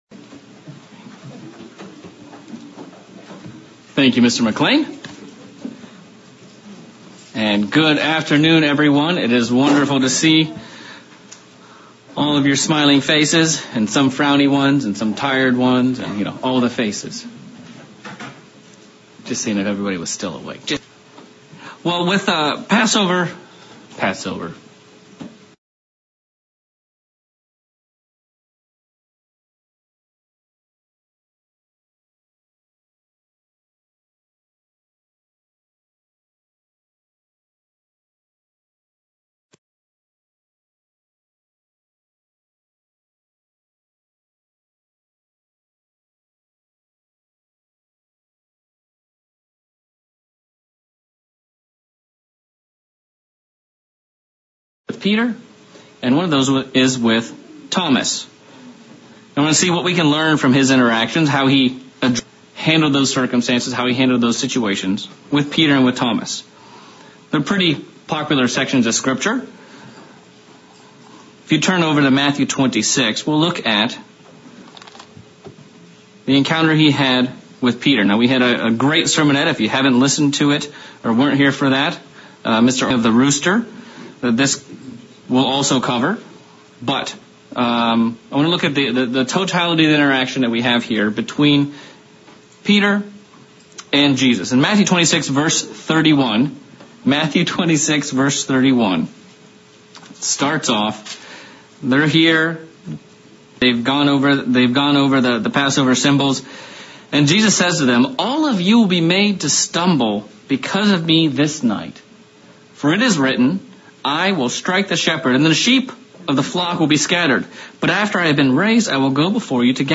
Continuing the sermon series on the time Christ spent with his disciples in the 40 days after his resurrections and preparing them for the day of Pentecost yet ahead of them